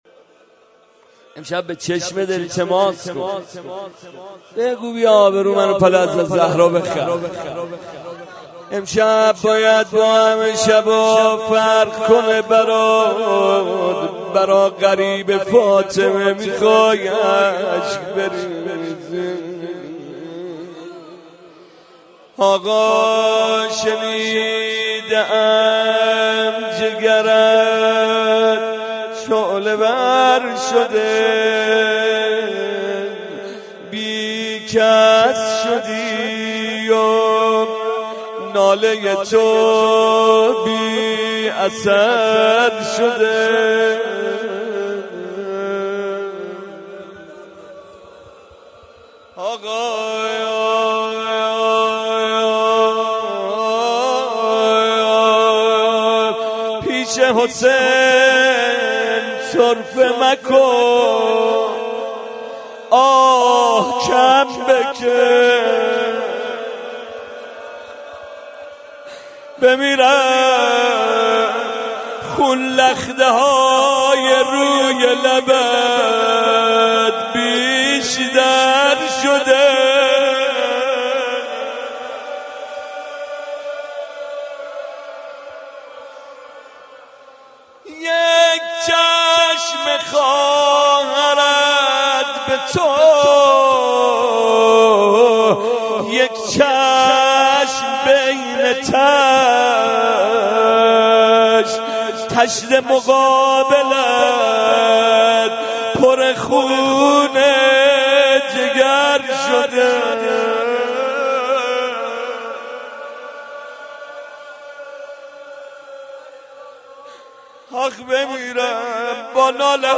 مراسم مناجات و سینه زنی شب اول ماه مبارک رمضان در حسینیه پیروان حضرت مهدی(عج) بصورت صوتی دریافت قسمت اول- مناجات دریافت قسمت دوم- روضه دریافت قسمت سوم- سینه زنی منبع : پایگاه اطلاع رسانی هیئت مکتب الزهرا(س)